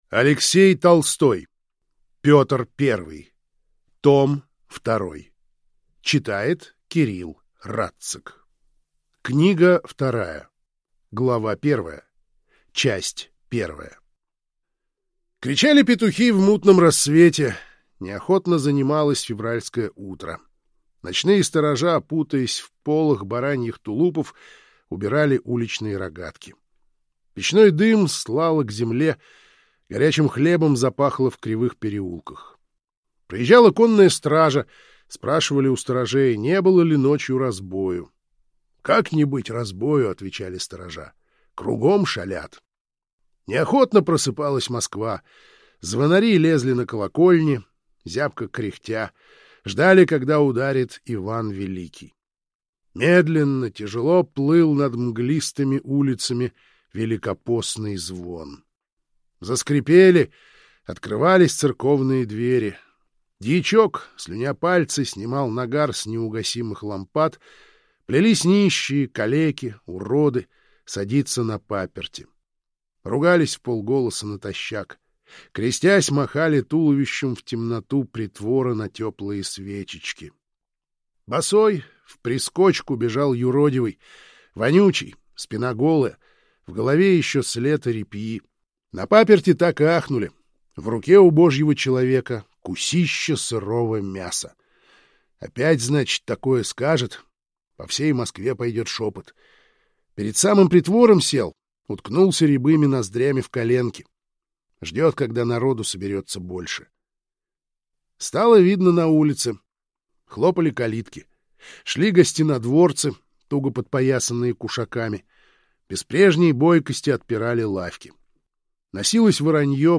Аудиокнига Петр Первый. Том 2 | Библиотека аудиокниг